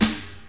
• Loud Snare Sample F Key 43.wav
Royality free snare tuned to the F note. Loudest frequency: 3525Hz
loud-snare-sample-f-key-43-1vl.wav